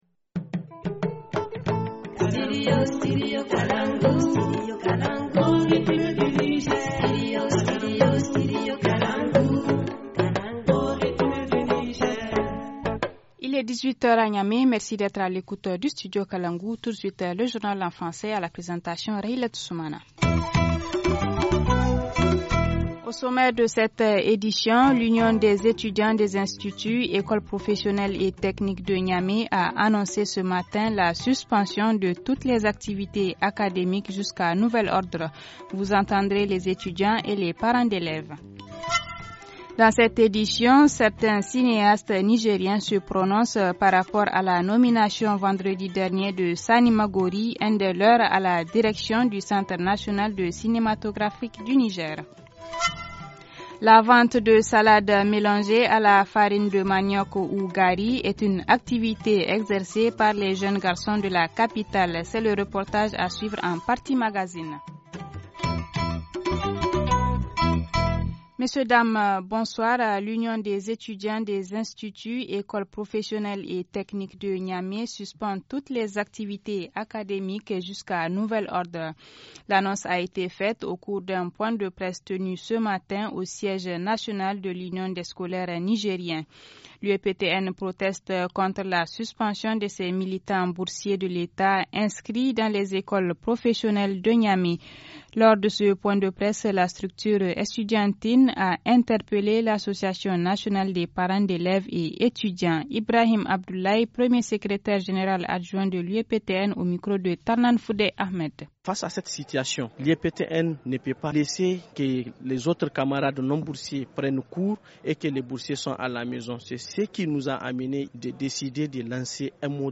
Journal du 22 Janvier 2018 - Studio Kalangou - Au rythme du Niger